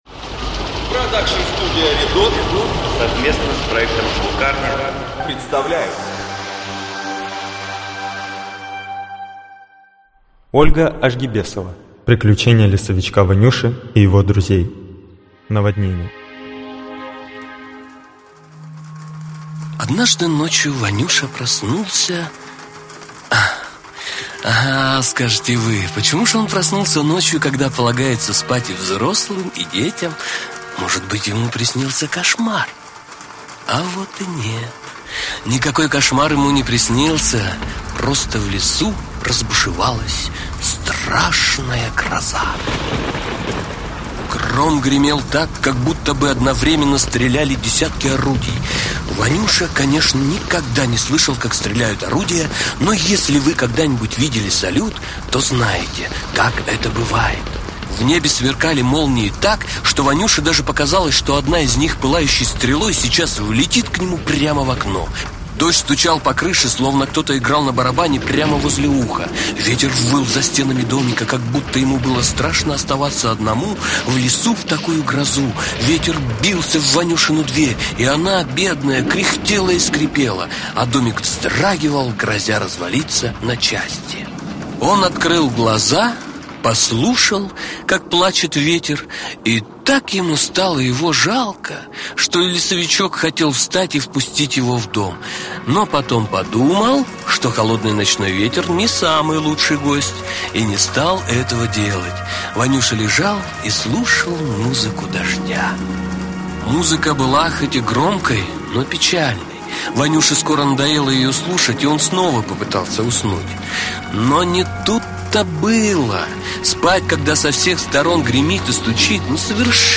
Аудиокнига Приключения Ванюши и его друзей. Наводнение. 3D-аудиосказка | Библиотека аудиокниг
Наводнение. 3D-аудиосказка Автор Ольга Ожгибесова Читает аудиокнигу Актерский коллектив.